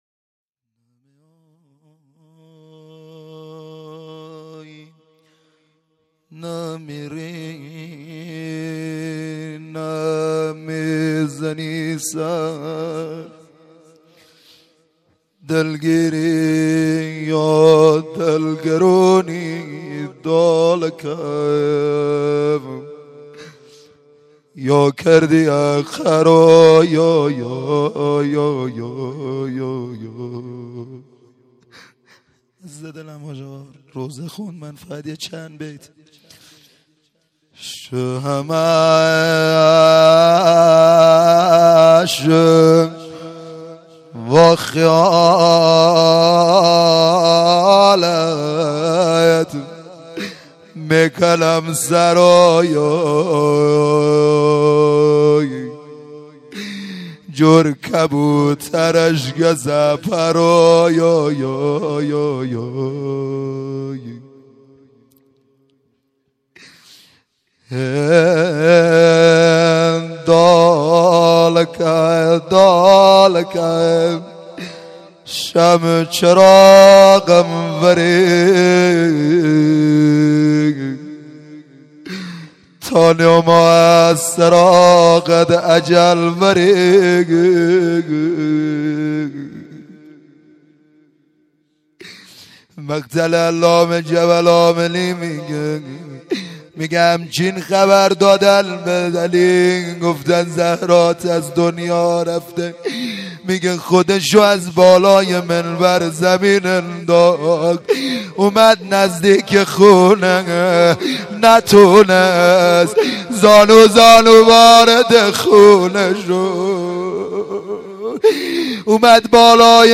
روضه لری